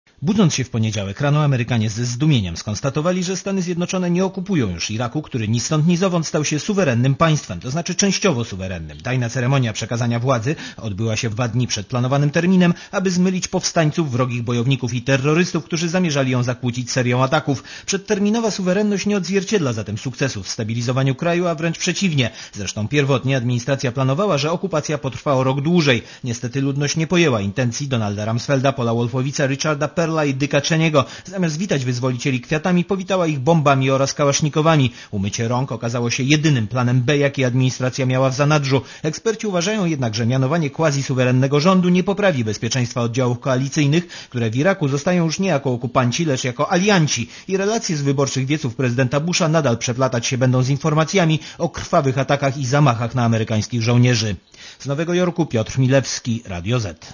Korespondencja z USA